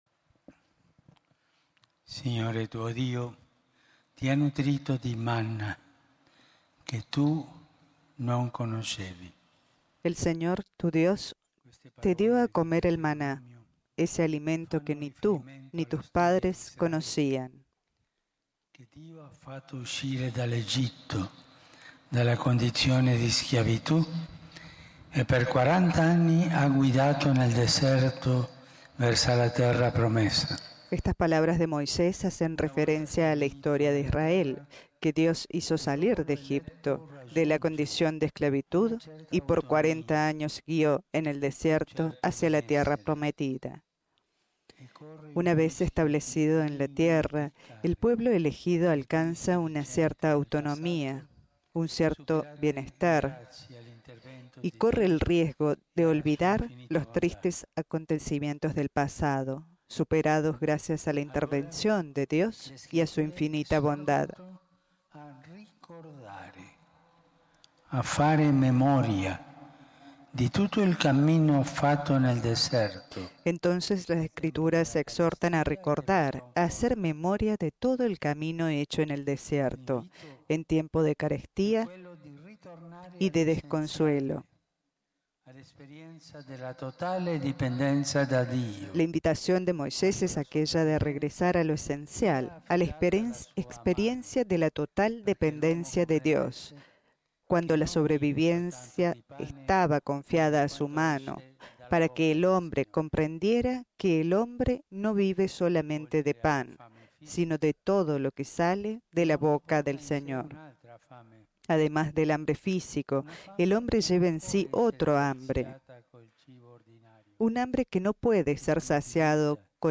Audio de la radio crónica de Radio Vaticano RealAudio